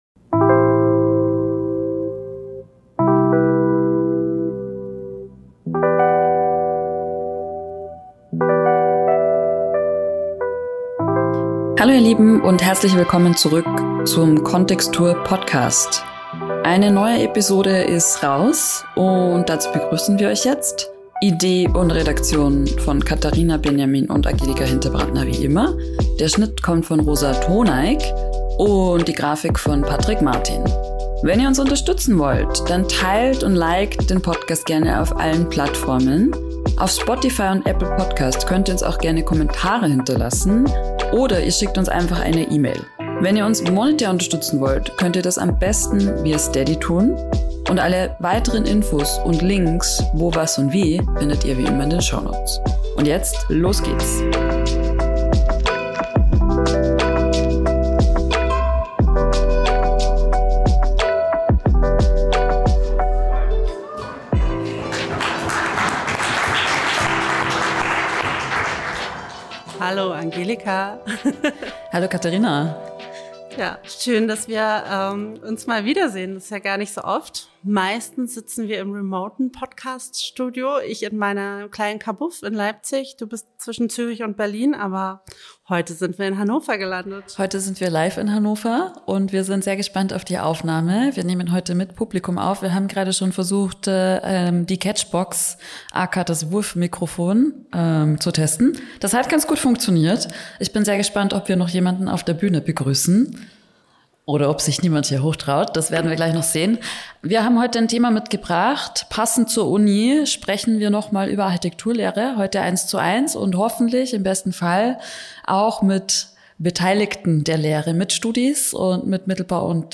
Die erste kntxtr podcast live Episode aus dem Hörsaal der Leibniz Universität Hannover.
Wichtiger Teil der Aufnahme war der unmittelbare Austausch mit dem studentischen Publikum u.a. zu folgenden Fragen: Welche Rolle spielen diese Themen in eurem Studium und zukünftigen Berufsleben?